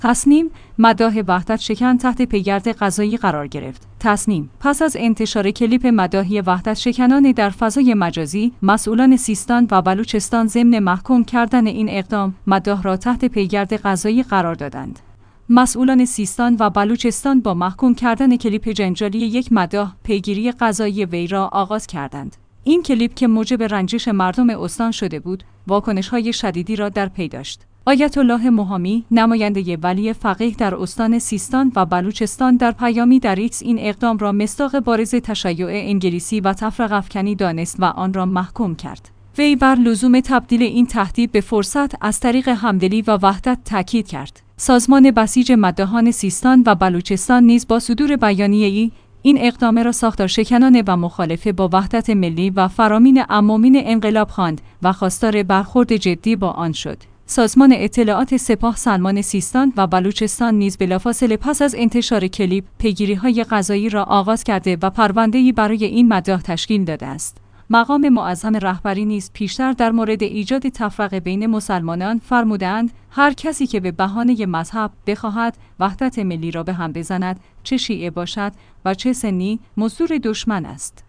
تسنیم: مداح وحدت‌شکن تحت پیگرد قضایی قرار گرفت